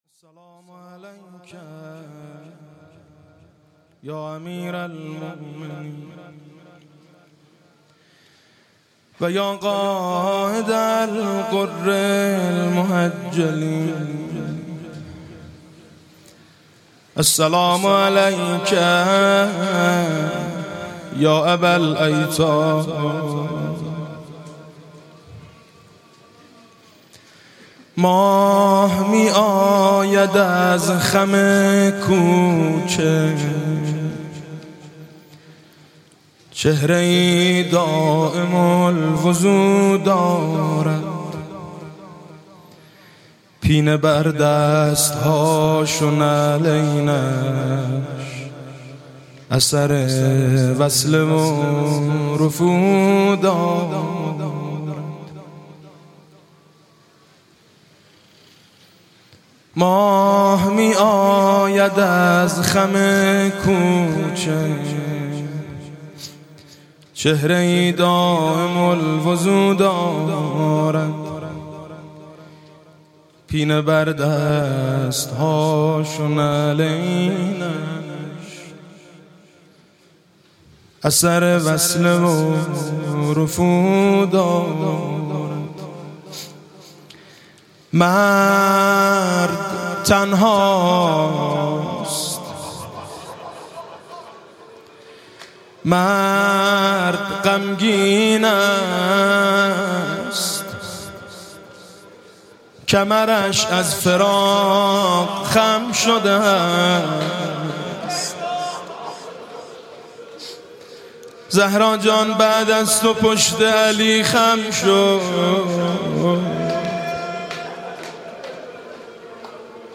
روضه: ماه می آید از خم کوچه